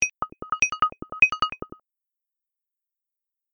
incoming_call.mp3